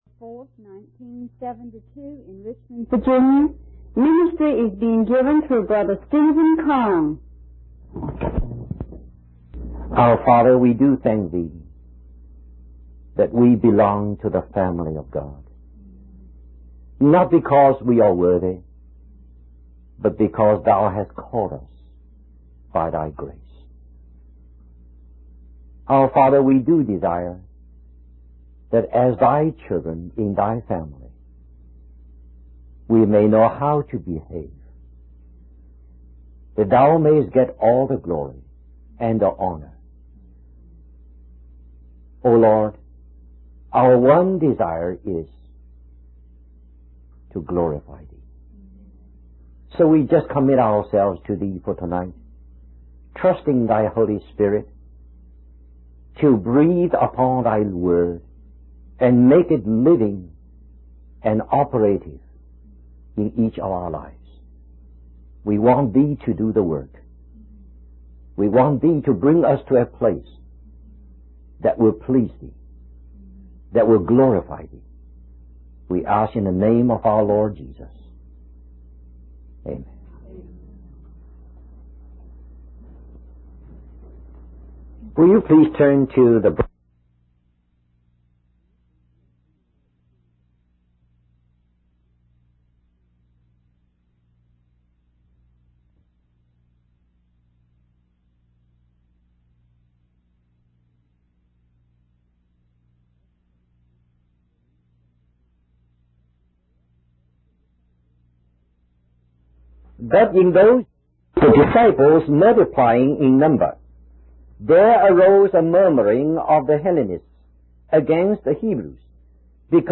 In this sermon, the speaker emphasizes the importance of each member of the church using their gifts and talents to serve God. He references Ephesians chapter 4 and Acts chapter 6 to illustrate the concept of coordinated and diverse service within the body of Christ.